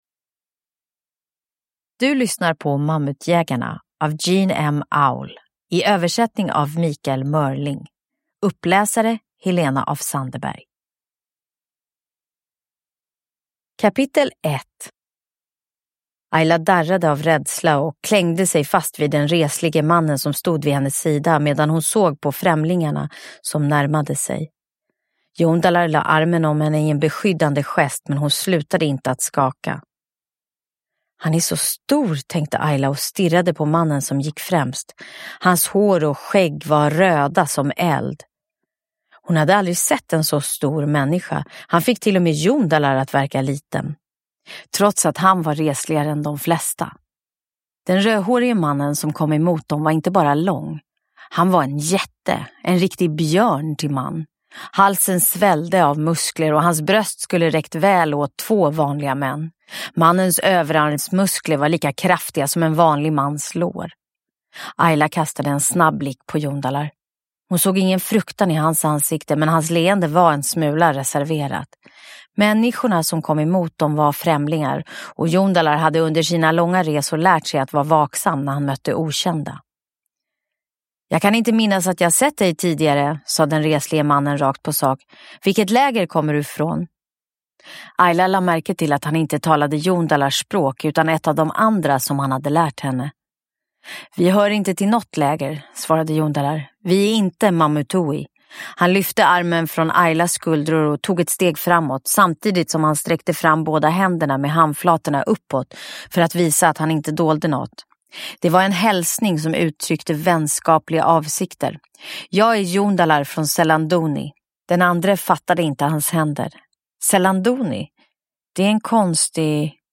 Mammutjägarna – Ljudbok – Laddas ner
Uppläsare: Helena af Sandeberg